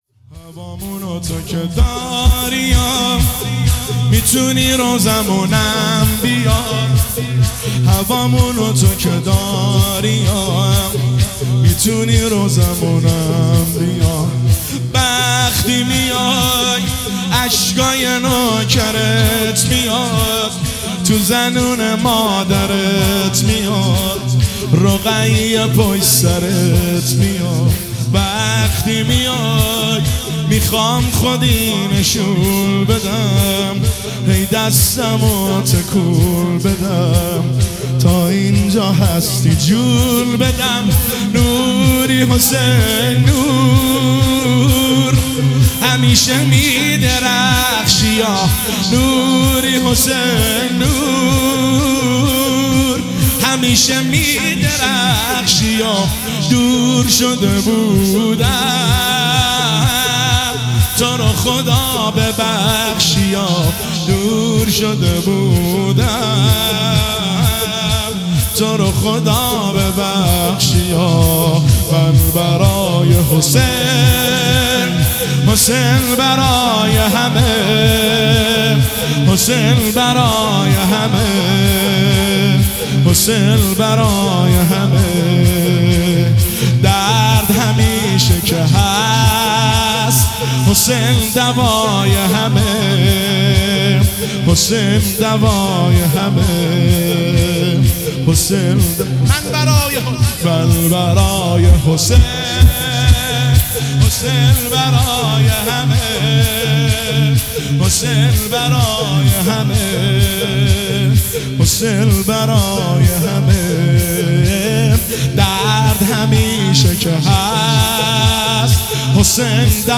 دانلود شور